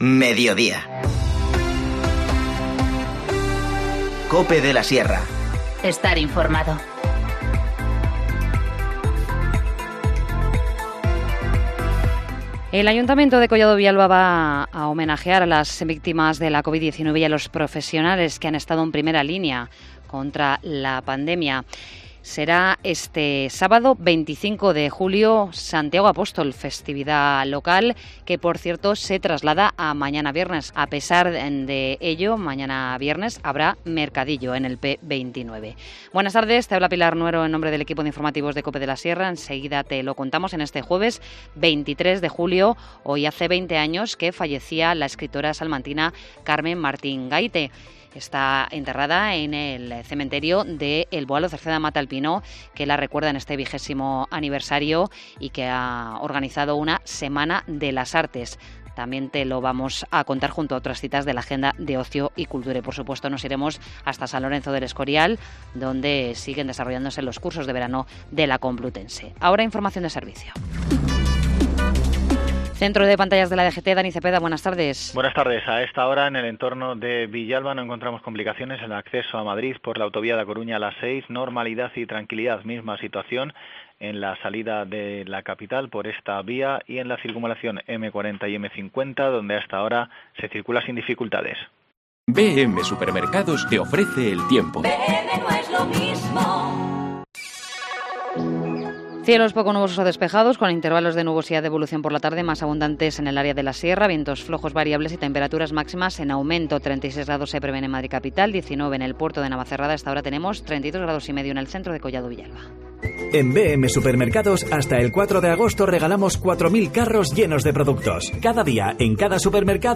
Informativo Mediodía 23 julio